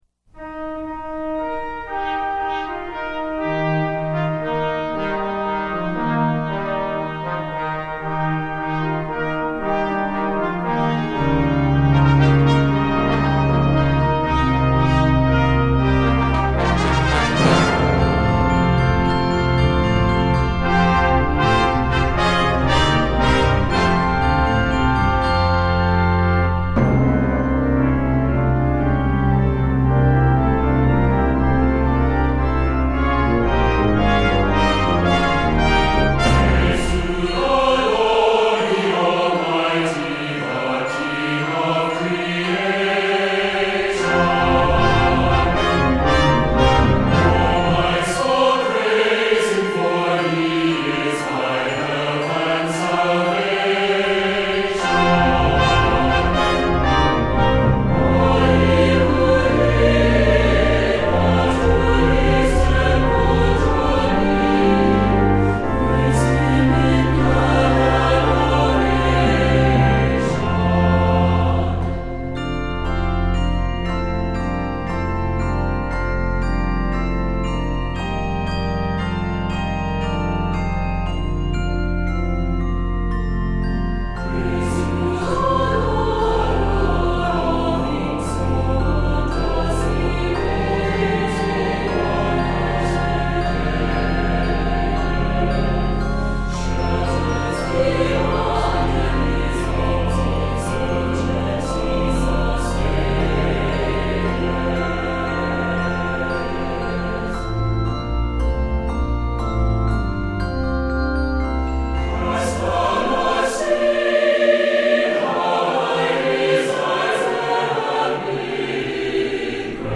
Octaves: 5